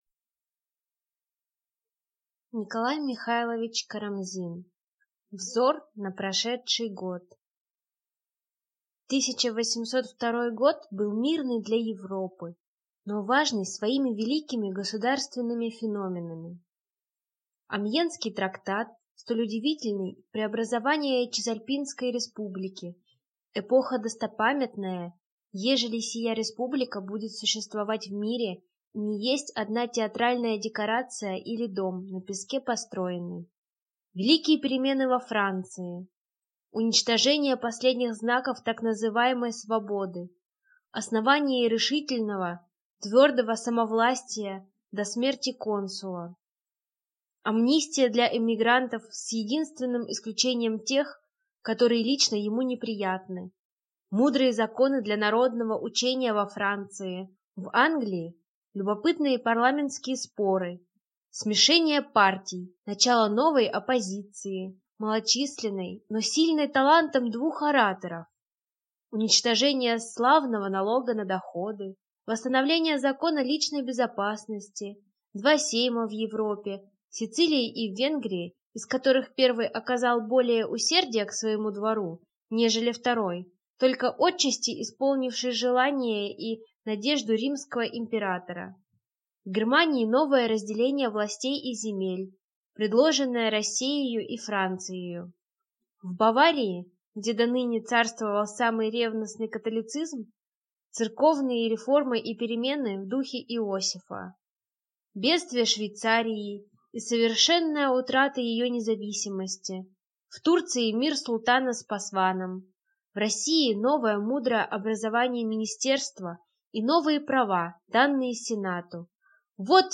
Аудиокнига Взор на прошедший год | Библиотека аудиокниг